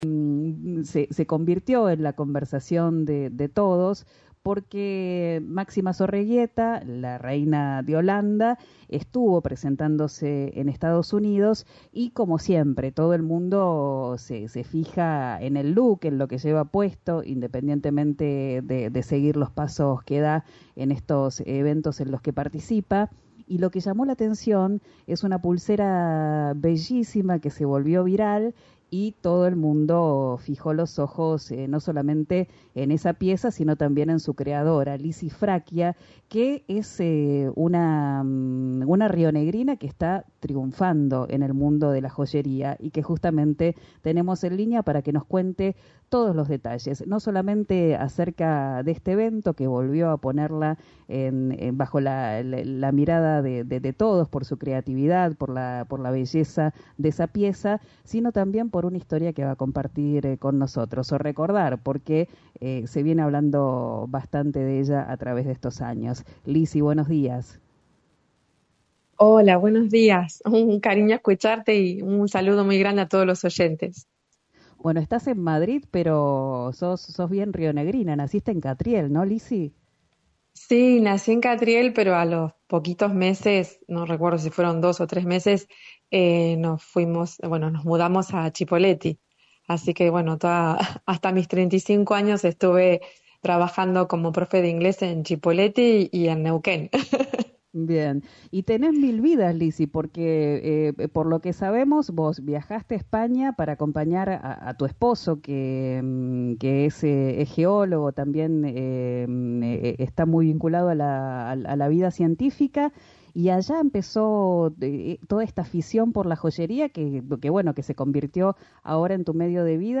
En diálogo con «Abran las ventanas» , de RÍO NEGRO RADIO